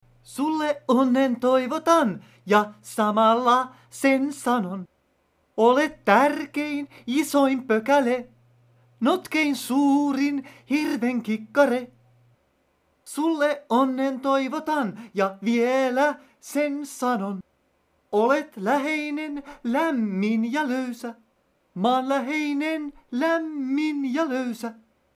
Hän on a cappella -lauluyhtye, jonka jäsenet ovat suurelta osin lahtelaistuneita.